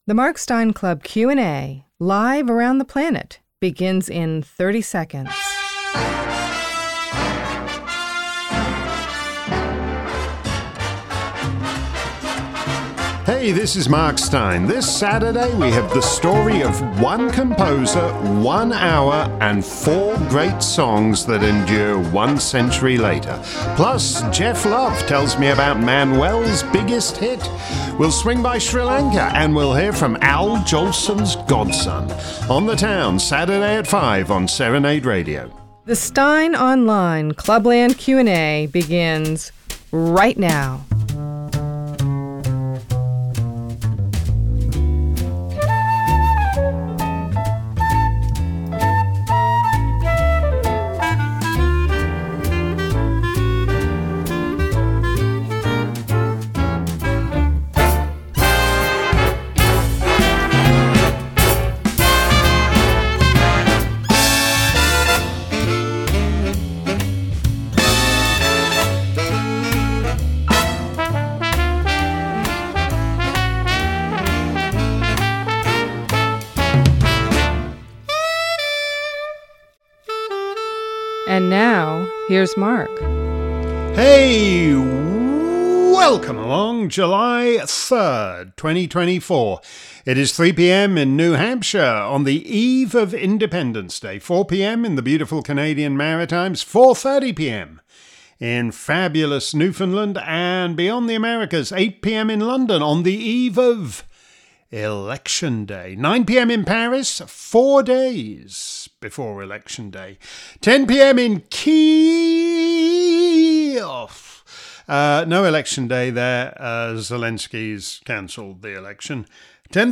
If you missed today's Clubland Q&A live around the planet, here's the action replay. Steyn was back at the microphone, fielding questions on many topics, including the "breaking" news about Biden's dead husk, the UK Tories' fourteen-year flopperoo, deathbed demographics and topless French feminists.